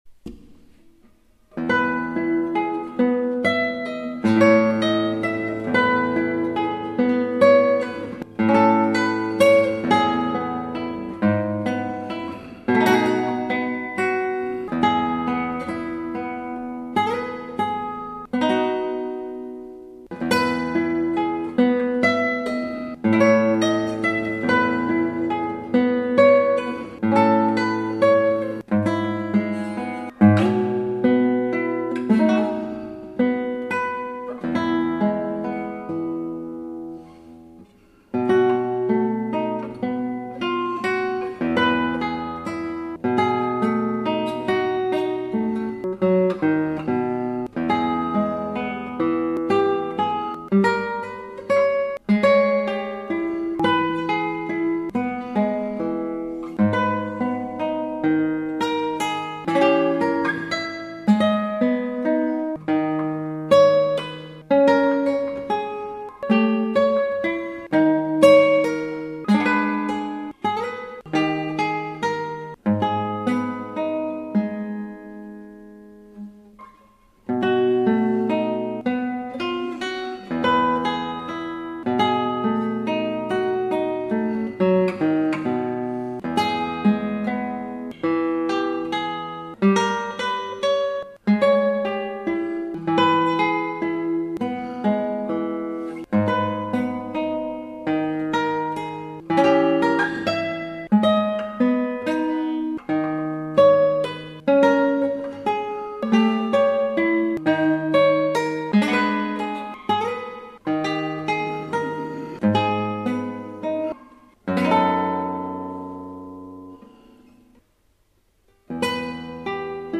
アルカンヘルで